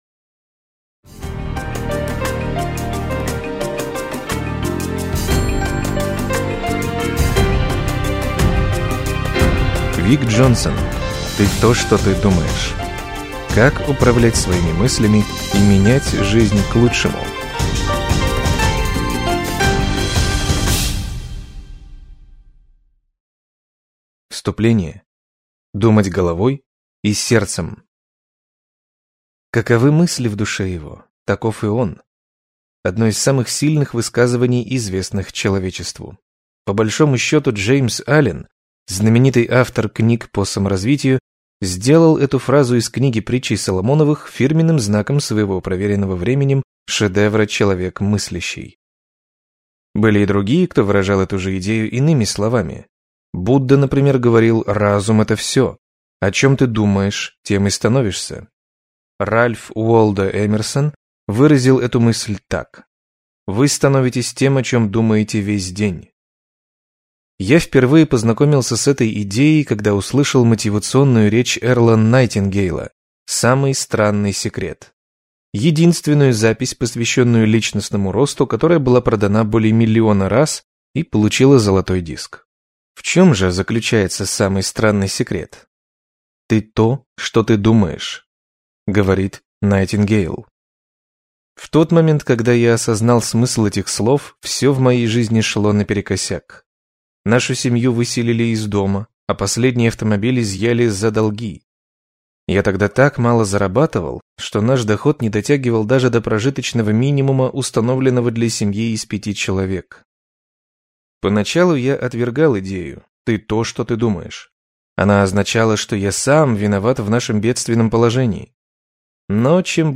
Аудиокнига Ты то, что ты думаешь. Как управлять своими мыслями и менять жизнь к лучшему | Библиотека аудиокниг